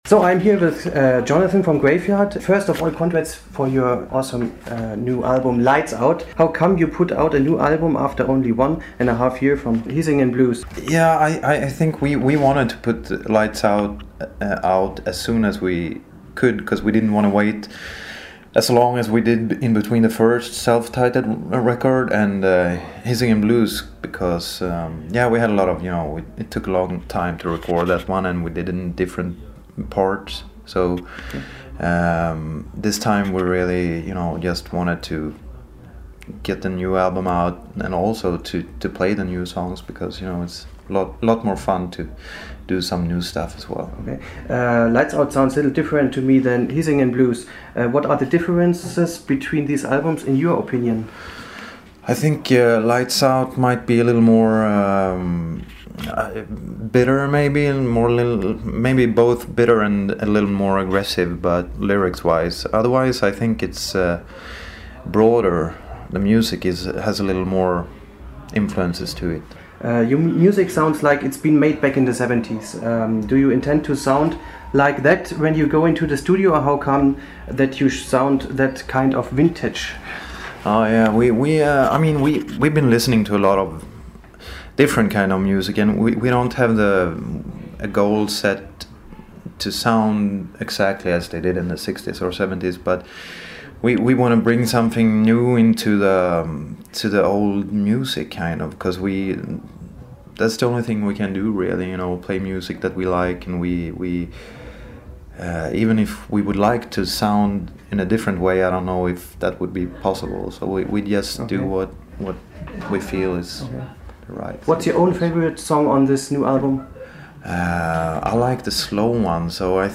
Ein Interview
iv_graveyard.mp3